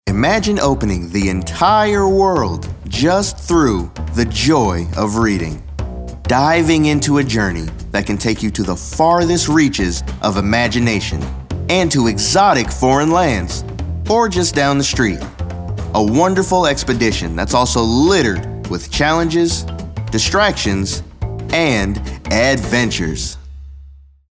announcer, caring, confessional, confident, conversational, friendly, genuine, Gravitas, inspirational, motivational, serious, smooth, thoughtful, warm